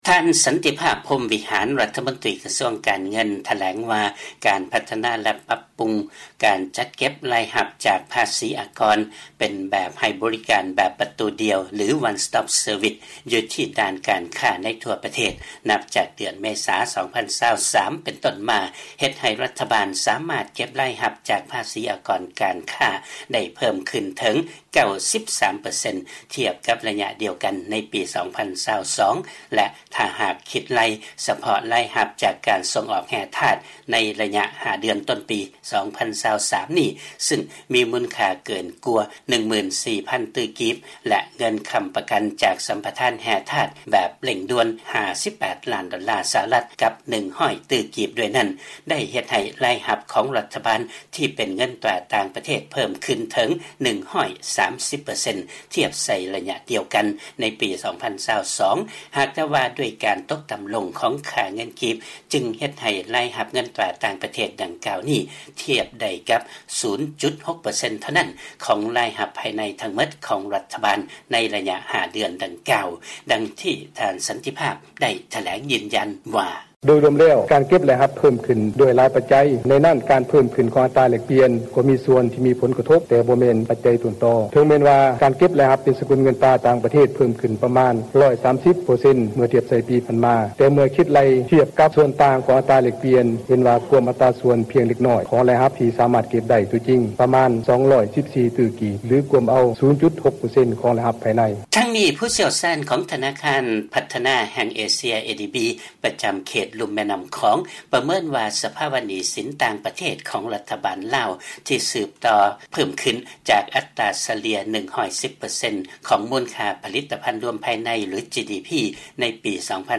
ເຊີນຮັບຟັງລາຍງານ ກ່ຽວກັບທຶນສຳຮອງເງິນຕາຕ່າງປະເທດຂອງລັດຖະບານລາວ ຫຼຸດລົງສູ່ລະດັບທີ່ເທົ່າກັບ ການນຳເຂົ້າສິນຄ້າຈາກຕ່າງປະເທດ ໄດ້ບໍ່ເຖິງ 2 ເດືອນ